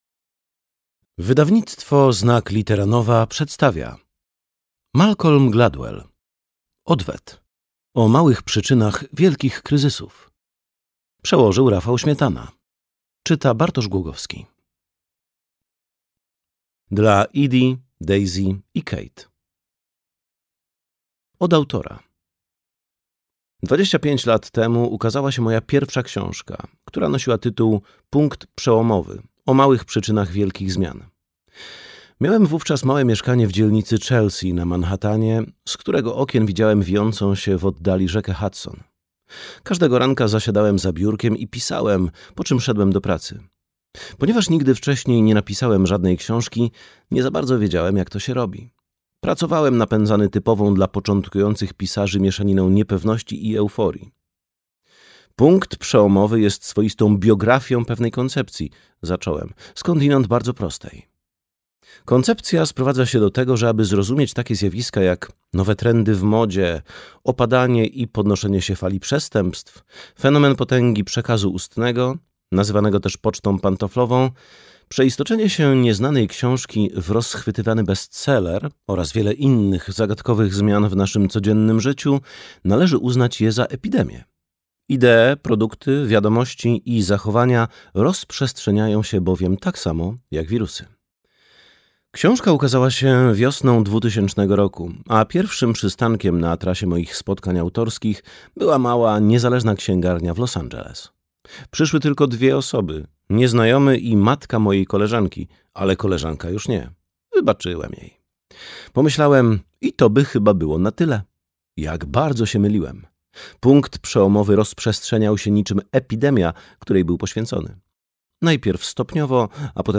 Odwet. O małych przyczynach wielkich kryzysów - Malcolm Gladwell - audiobook